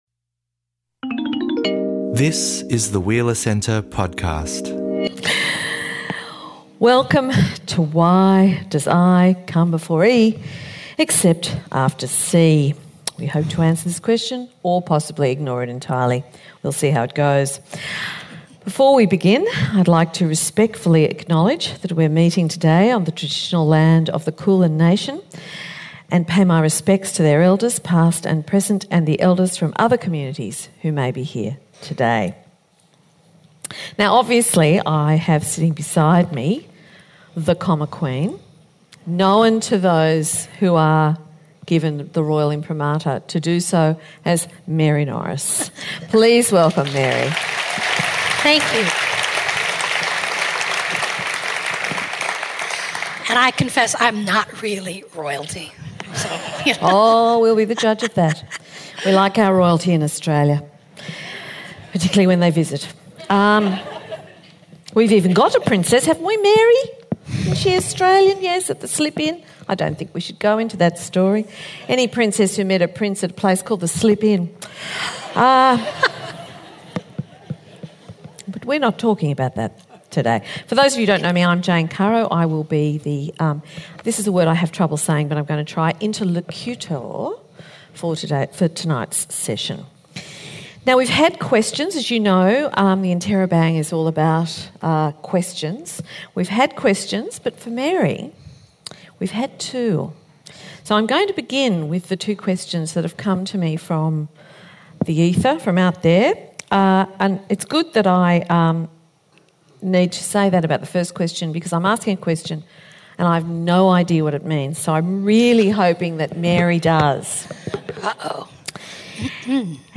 In conversation with Jane Caro at The Interrobang, Norris shared the story of her ascendance to the throne of the comma queen.